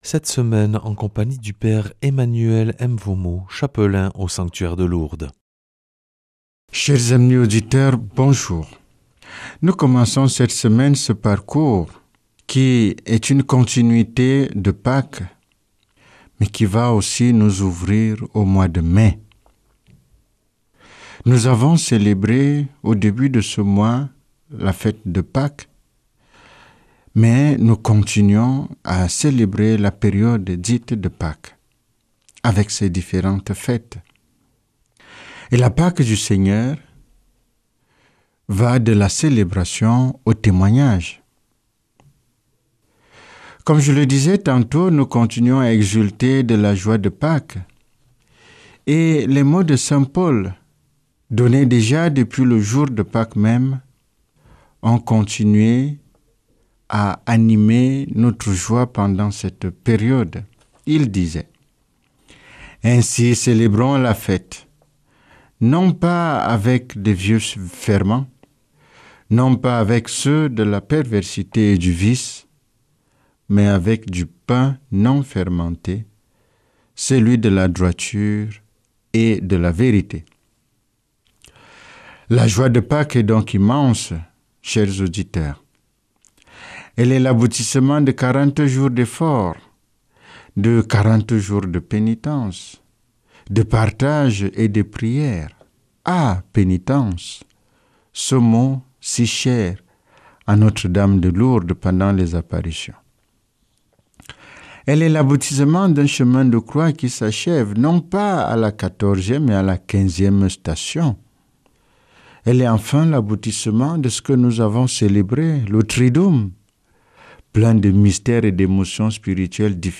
lundi 27 avril 2026 Enseignement Marial Durée 10 min